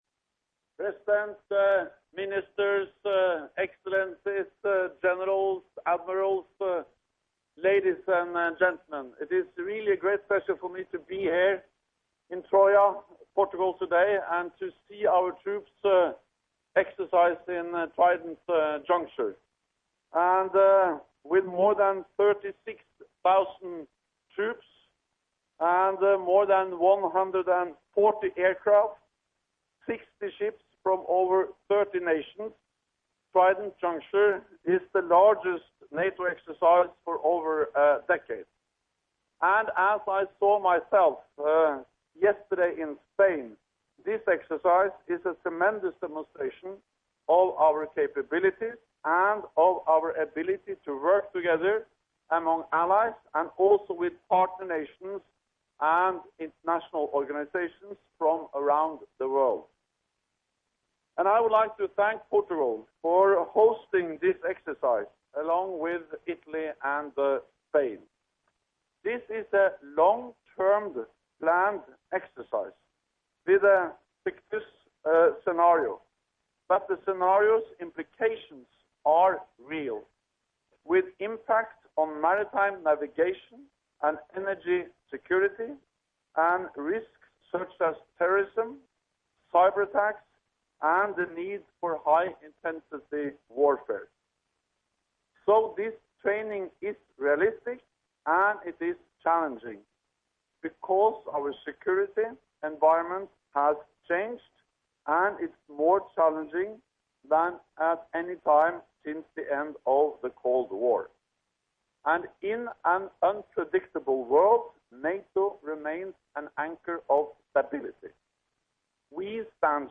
Opening remarks by NATO Secretary General Jens Stoltenberg at the Trident Juncture 2015 Distinguished Visitor's Day in Troia, Portugal